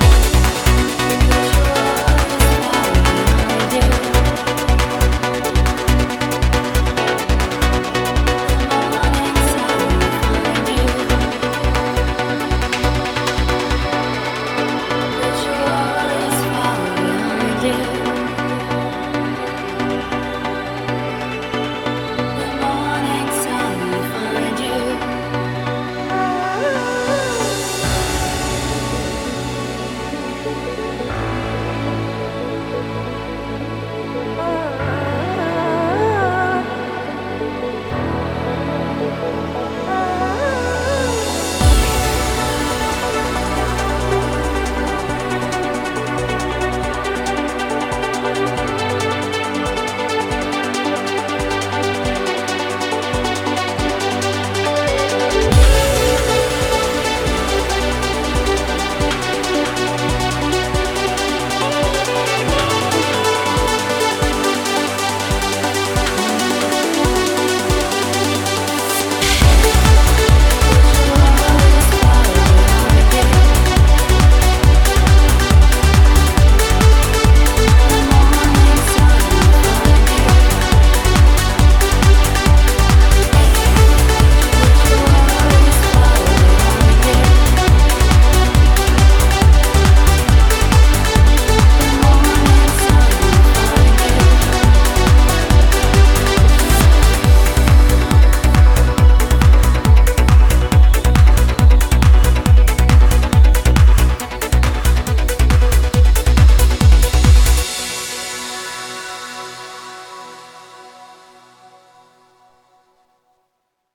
BPM138
Audio QualityMusic Cut
Nonetheless, an absolutely beautiful upbeat trance song!